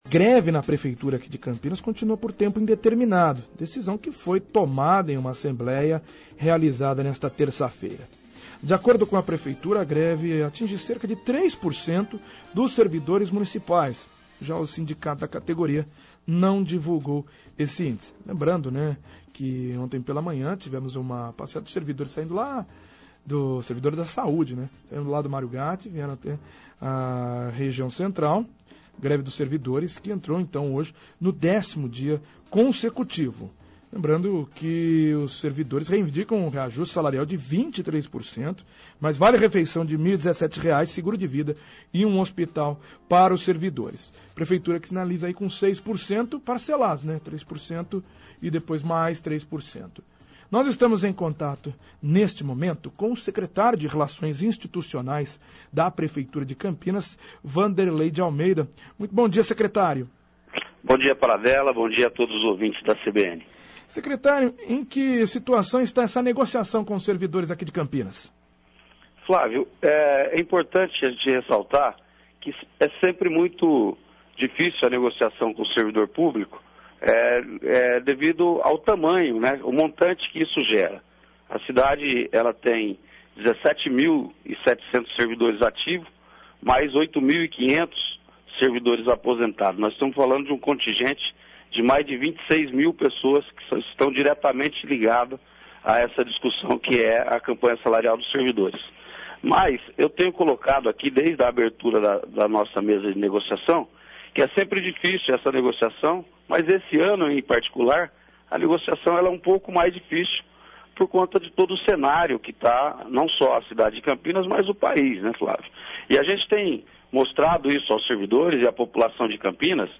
Secretário de relações institucionais de Campinas, Wanderley de Almeida, o Wandão, fala sobre a greve dos servidores da Prefeitura de Campinas - CBN Campinas 99,1 FM